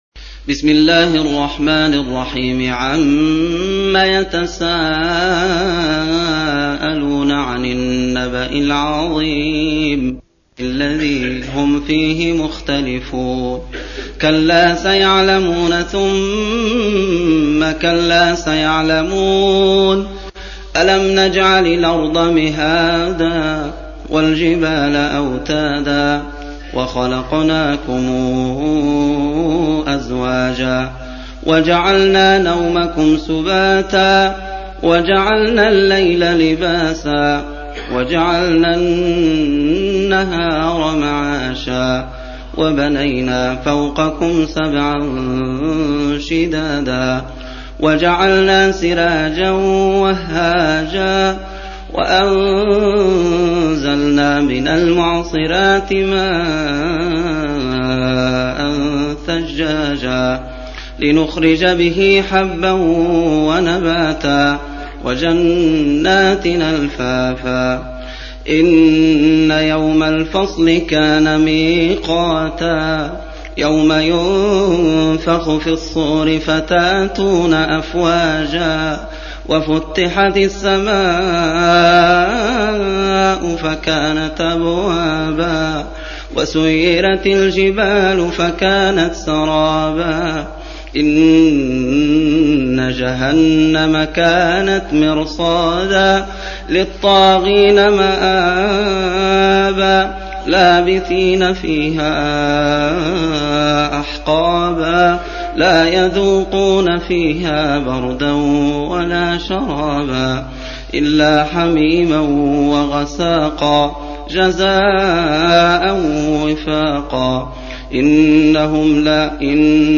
Surah Sequence تتابع السورة Download Surah حمّل السورة Reciting Murattalah Audio for 78. Surah An-Naba' سورة النبأ N.B *Surah Includes Al-Basmalah Reciters Sequents تتابع التلاوات Reciters Repeats تكرار التلاوات